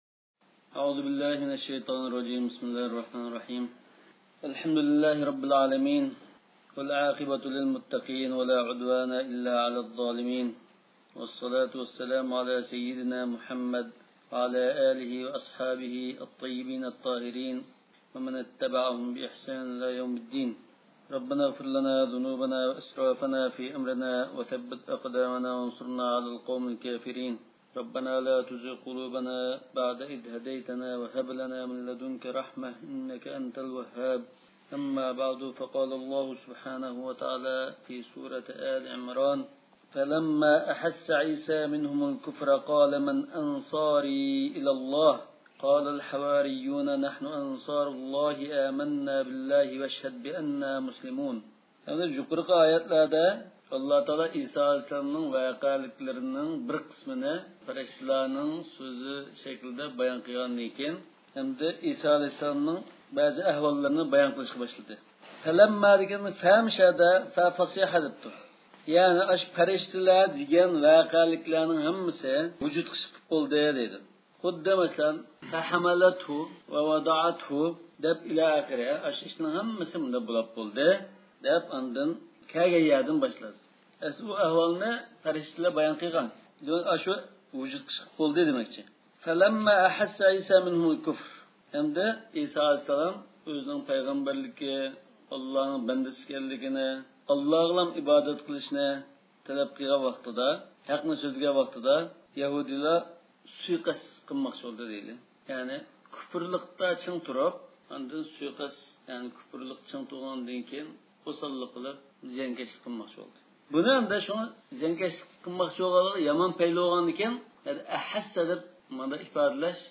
ئاۋازلىق دەرسلەر